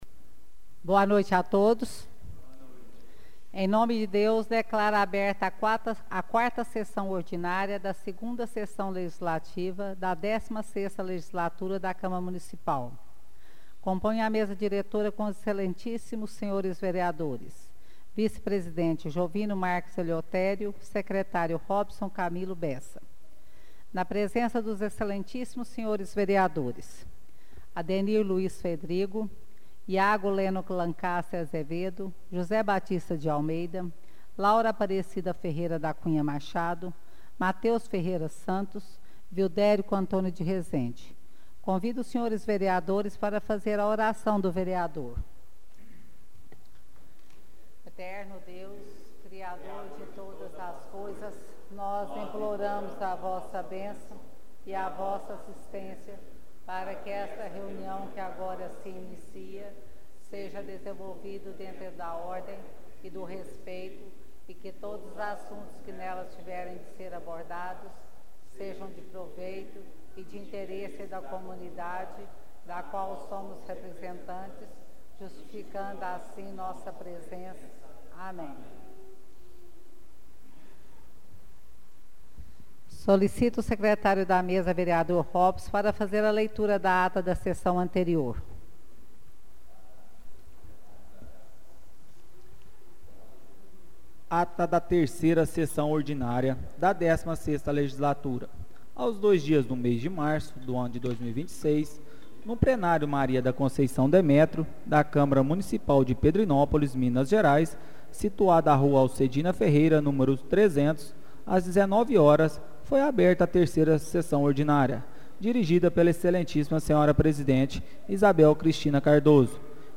Áudio da 4ª Sessão Ordinária de 2026